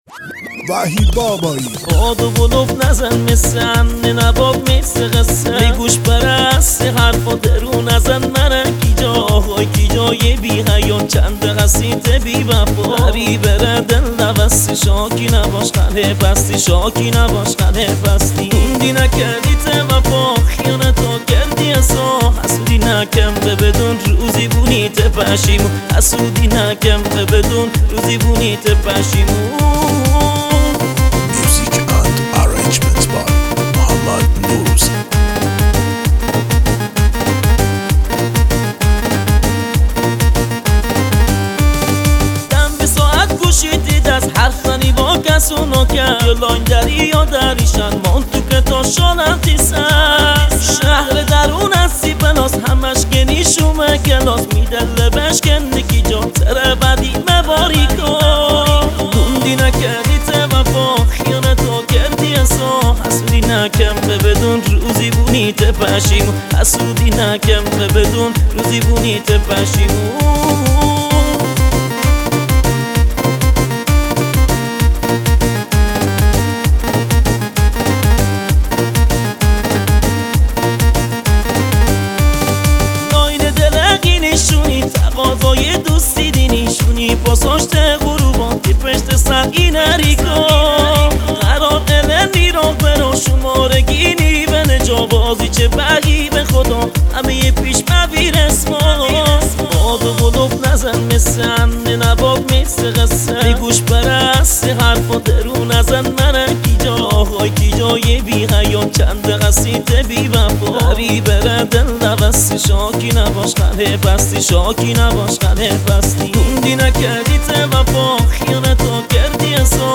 آهنگ مازندرانی جدید
آهنگ شاد